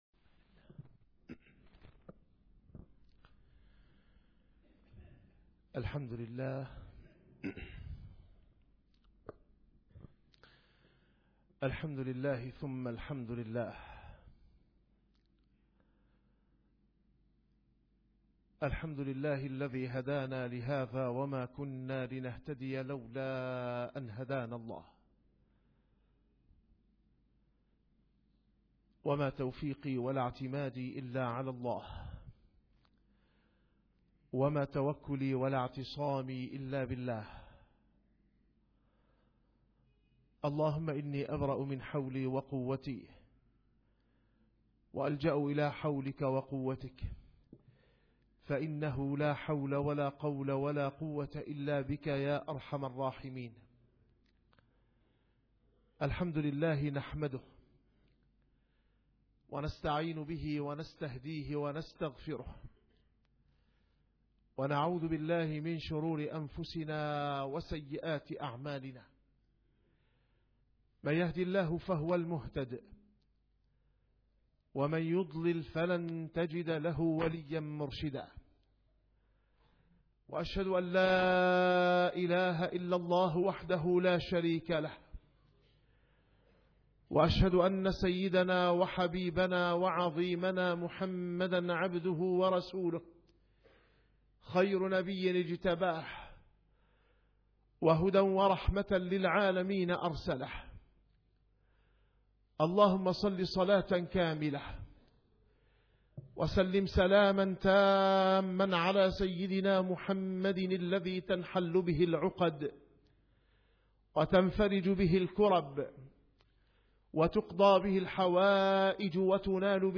- الخطب - منهج الهجرة في فهم رسول الله صلى الله عليه وسلم(توطئة)